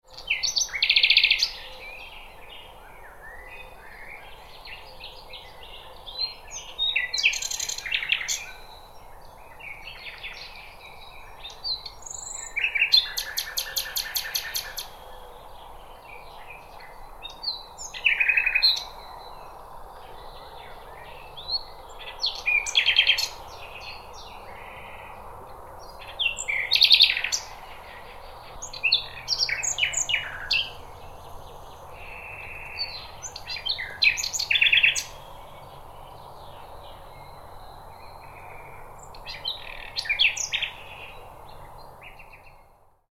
Wild-bird-songs-of-the-spring-forest.mp3